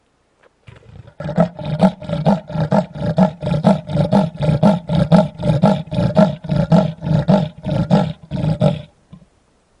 leopard-sawing-call.m4a